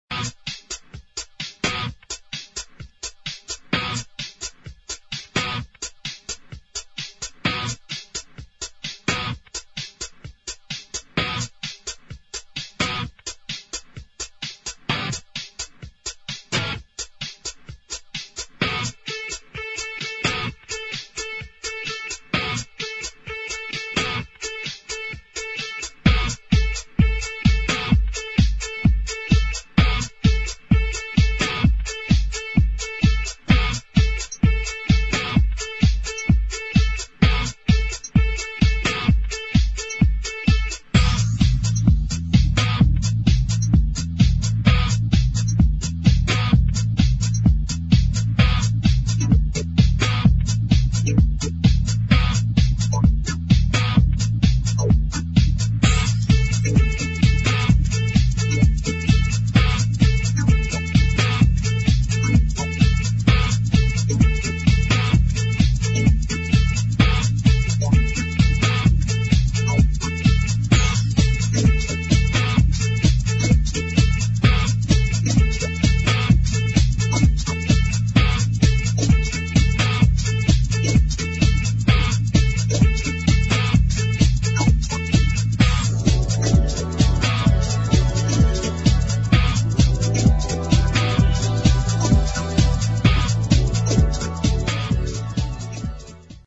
[ FRENCH / ELECTRO / HOUSE ]
グルーヴィーなディスコ・ハウスやロッキン・エレクトロ、ダウンテンポ・ブレイクビーツ等、幅広い音楽性が秀逸！！